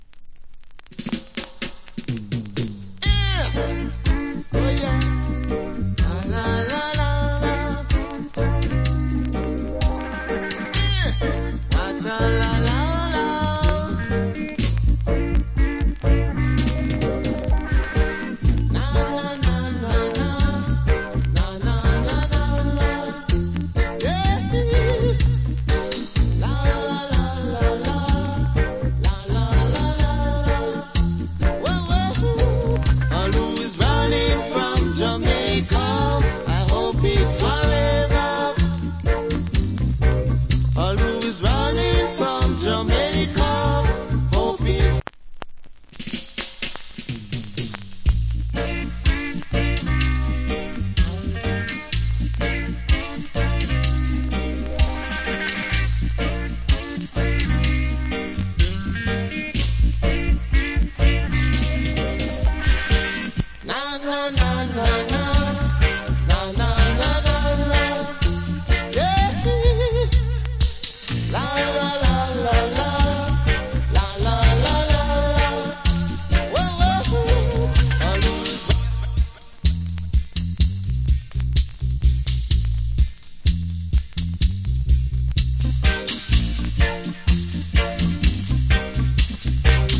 Nice Roots Vocal. Rec At Black Ark.